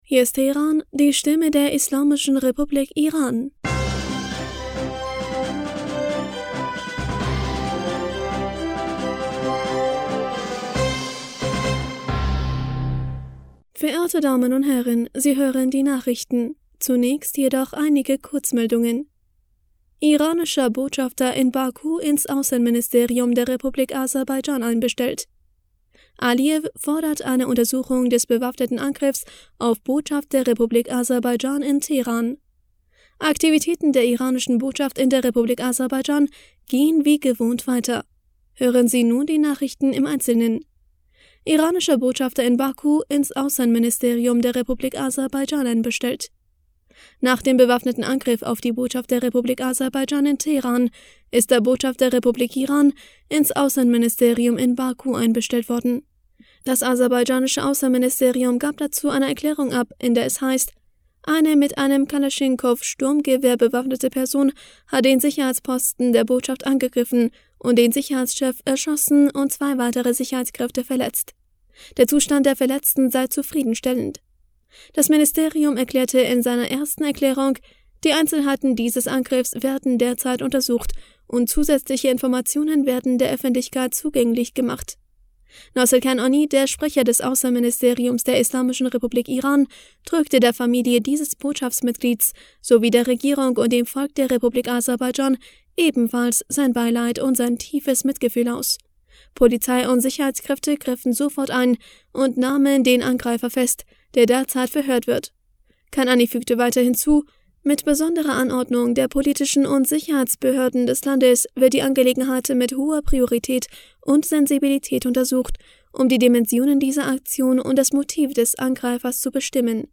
Nachrichten vom 28. Januar 2023